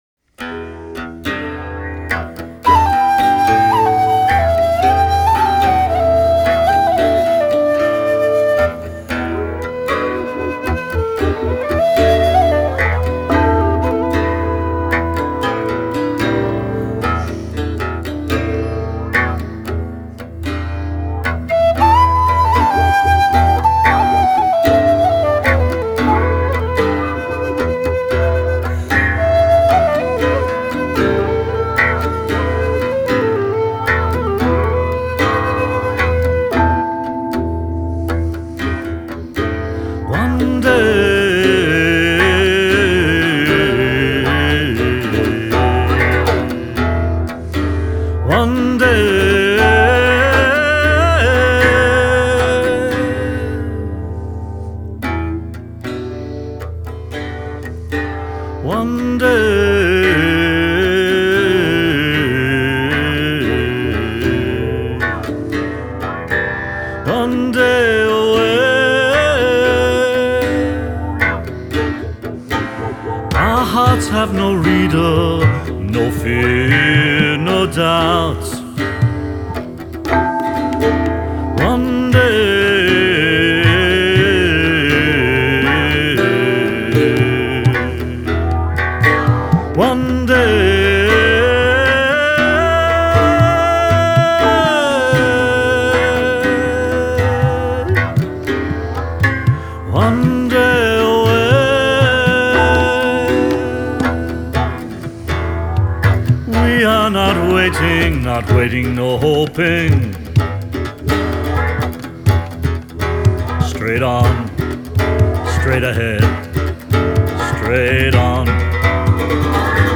Gendung (Indonesian Drums)
Xylopt and drum kit